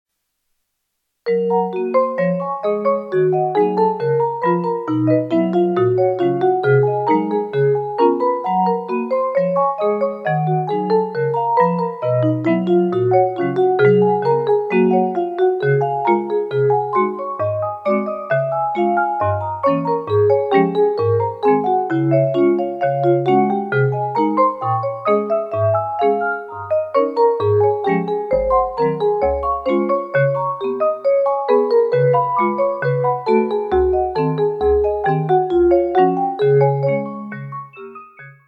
蝶々という愛称で親しまれるように、とても愛らしい曲。ひらひらと蝶が舞うような、軽やかで可愛らしい曲なので、たまには、こんな音色もよいかと、ヴィブラフォンの音で（本物のヴィブラフォンを演奏しているわけではなく、YAMAHA P-90の内蔵サンプリング音色ですが）。